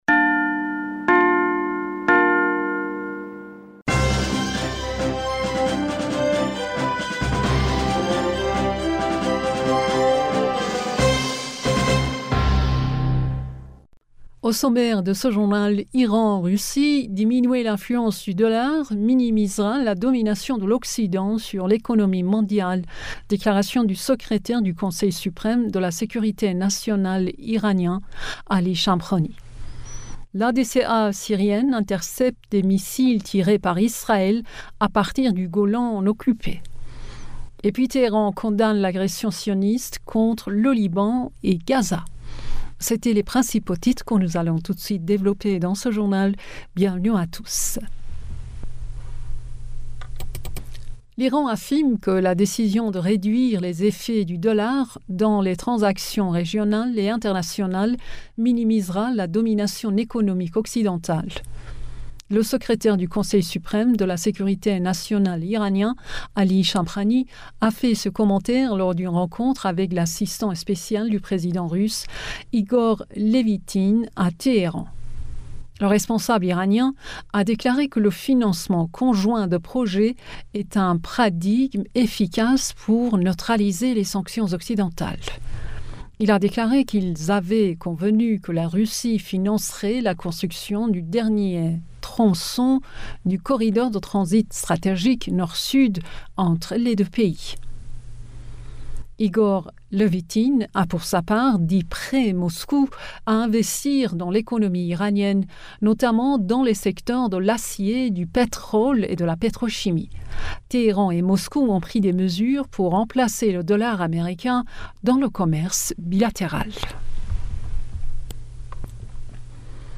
Bulletin d'information du 09 Avril 2023